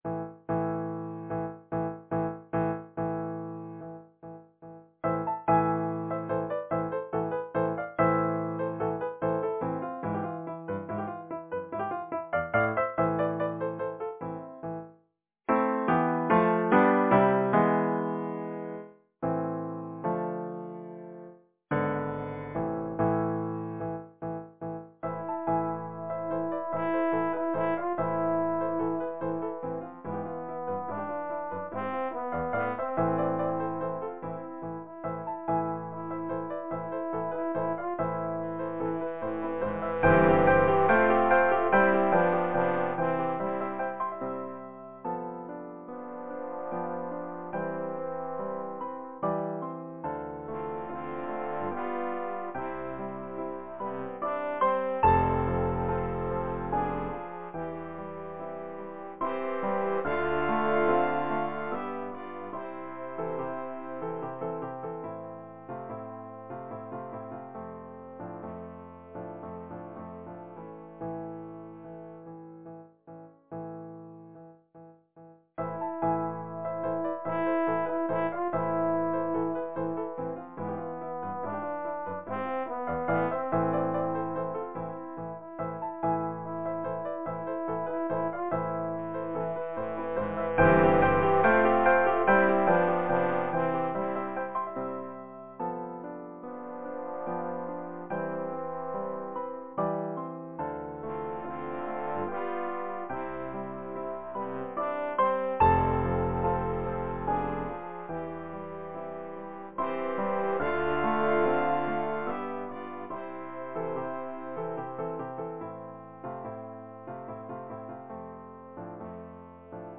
Title: Spanish Serenade Composer: Edward Elgar Lyricist: Henry Wadsworth Longfellow Number of voices: 4vv Voicing: SATB Genre: Secular, Partsong
Language: English Instruments: Piano